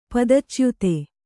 ♪ padachyute